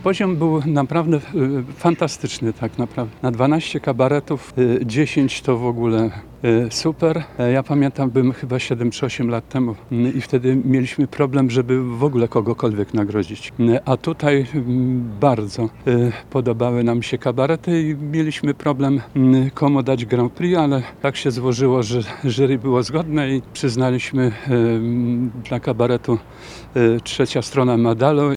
-Tegoroczny przegląd kabaretowy stał na bardzo wysokim poziomie- mówi Krzysztof Daukszewicz członek jury.